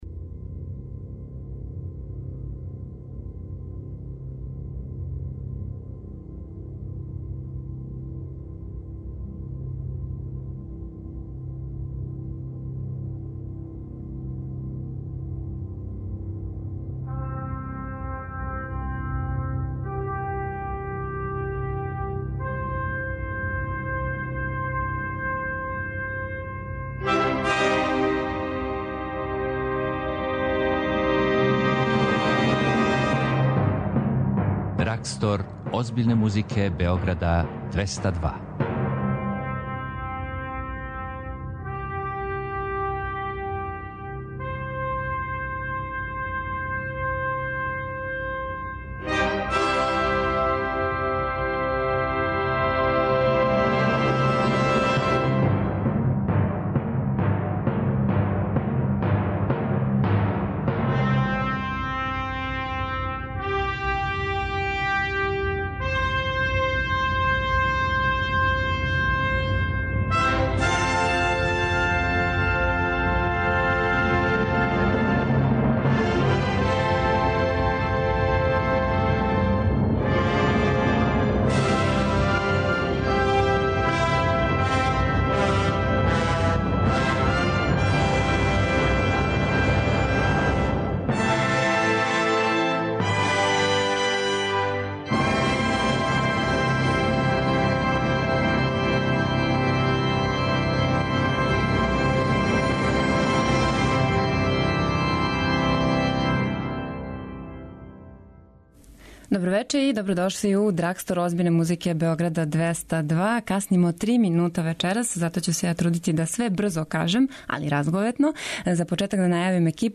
Први јесењи Драгстор започињемо Вивалдијевим концертом који слави ово годишње доба, а настављамо са симфонијском музиком Моцарта и Бетовена и делима за клавир Рахмањинова и Скрјабина.
концерт из галерије Артгет
сонате за виолину и клавир